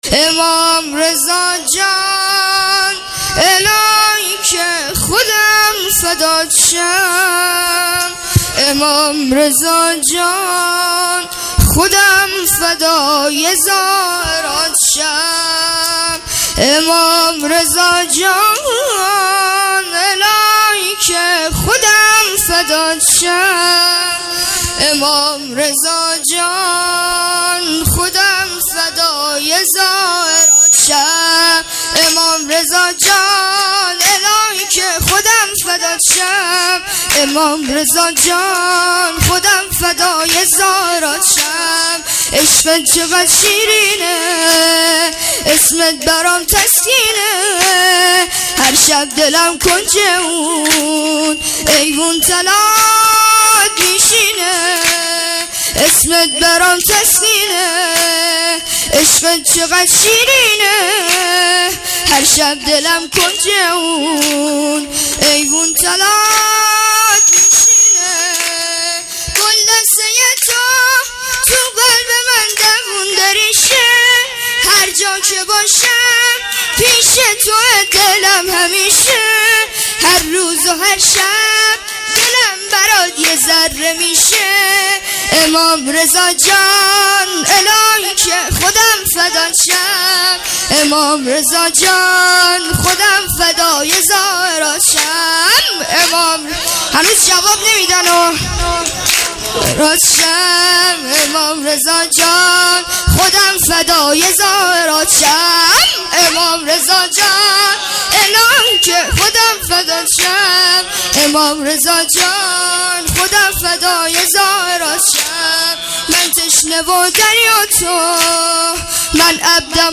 جشن دهه کرامت -28 تیر 97